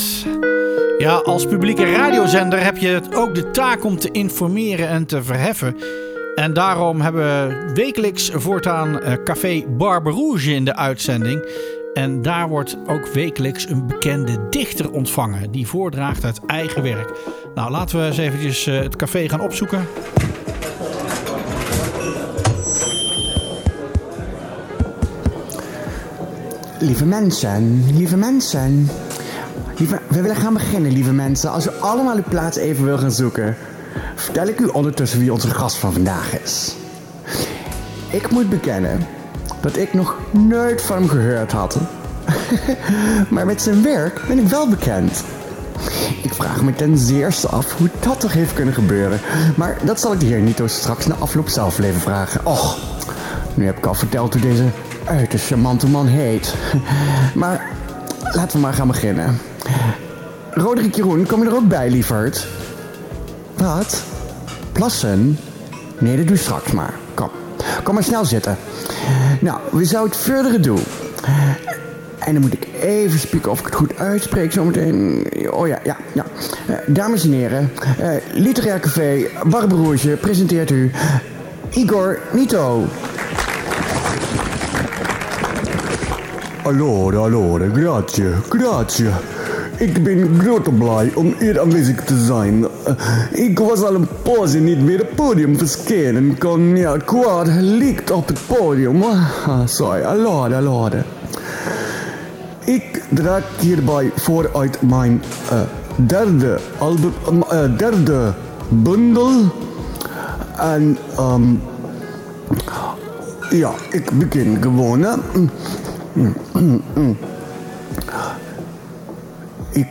Het café ontvangt elke week een bekende dichter, die voordraagt uit eigen werk. Tegelijkertijd volgen we de ontwikkelingen rond de organisatie van het literair café en zijn bezoekers.